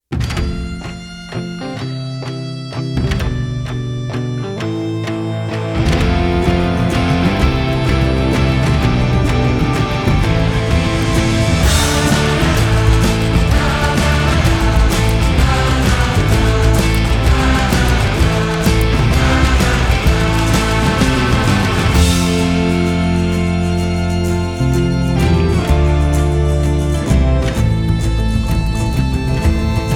TV Soundtrack
Жанр: Соундтрэки